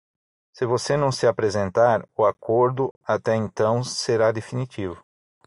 Pronounced as (IPA)
/aˈkoʁ.du/